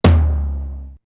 Index of dough-samples/ tidal-drum-machines/ machines/ LinnLM2/ linnlm2-mt/
LM-2_TOM_2_TL.wav